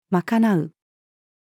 賄う-female.mp3